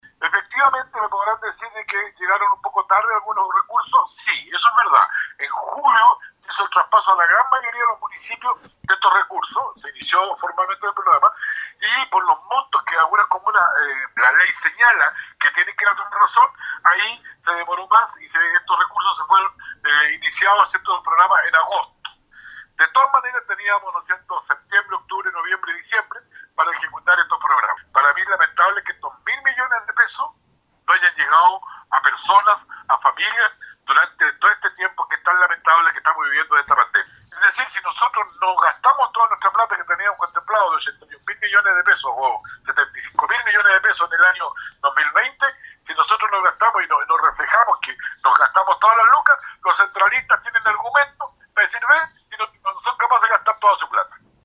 Así lo estableció en diálogo con radio Estrella del Mar el presidente del Core, Juan Cárcamo.